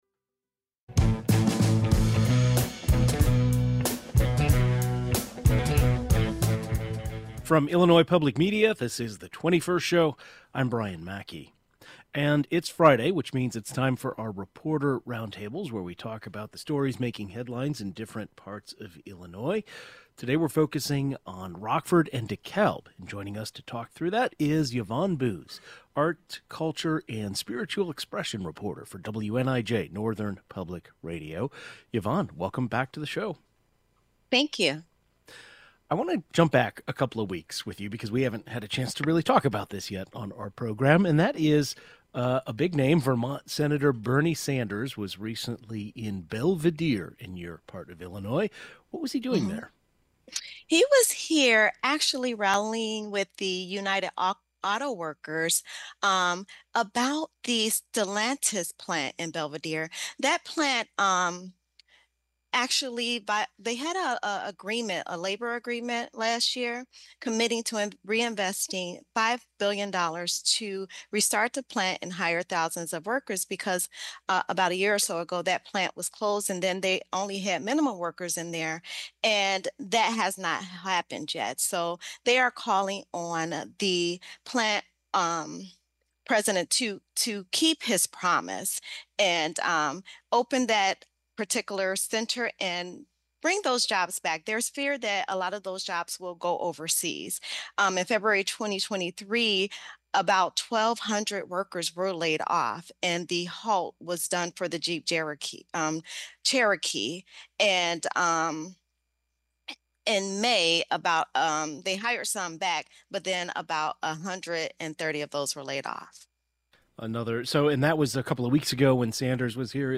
Reporter Roundtable: Bernie Sanders comes to Belvidere and Northern Illinois artists in the spotlight